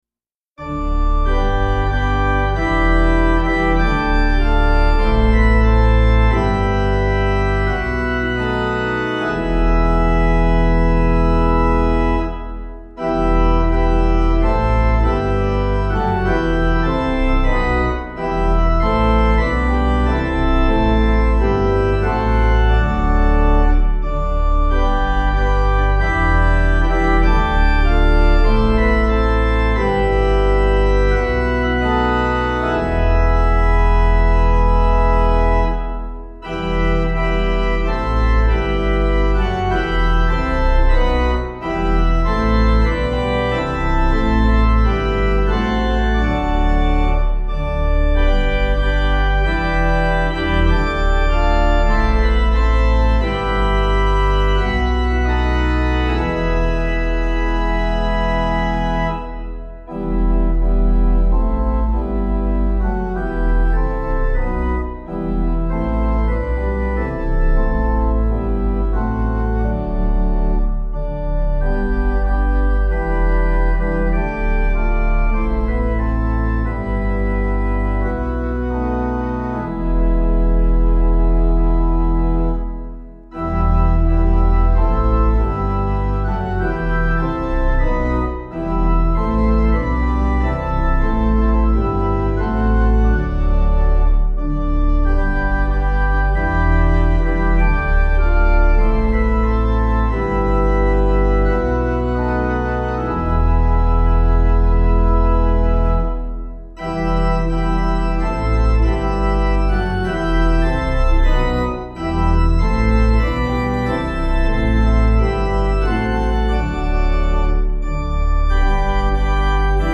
Meter: 8.8.8.4
Key: e minor
Source: Traditional Jewish melody